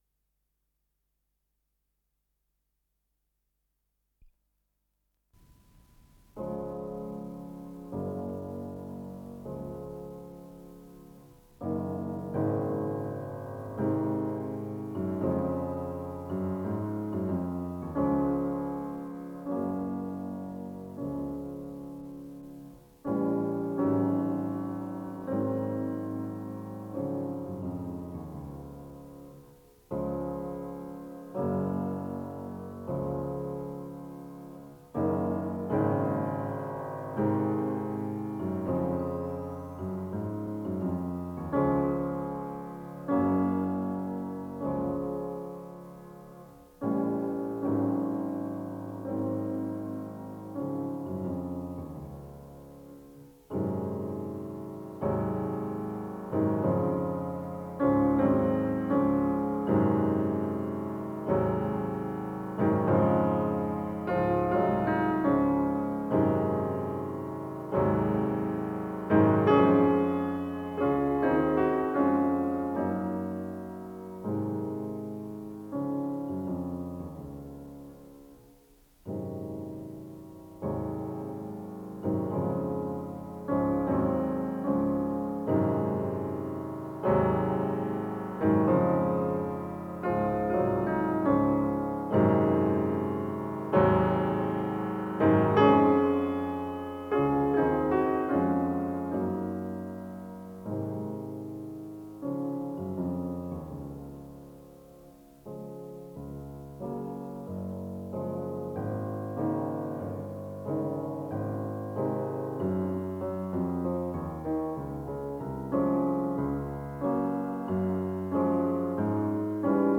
Исполнитель: Вера Горностаева - фортепиано
Для фортепиано
фа минор